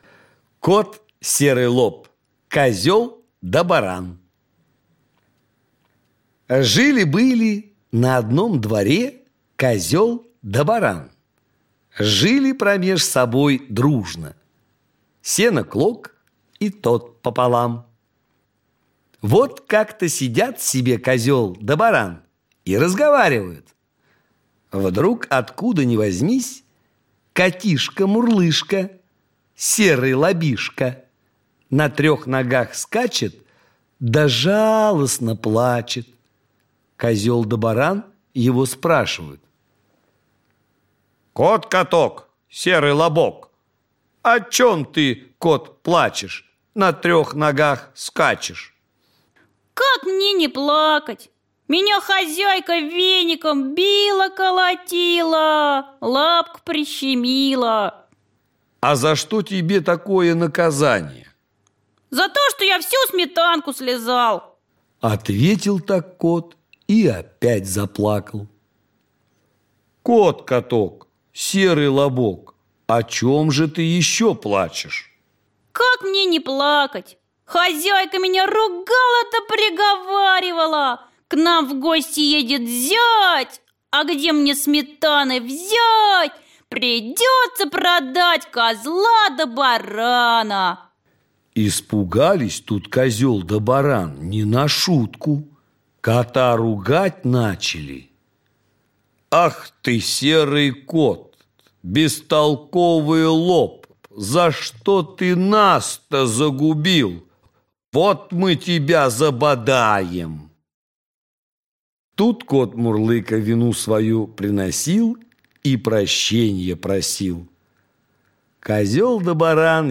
Аудиокнига Теремок | Библиотека аудиокниг